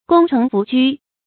功成弗居 注音： ㄍㄨㄙ ㄔㄥˊ ㄈㄨˊ ㄐㄨ 讀音讀法： 意思解釋： 見「功成不居」。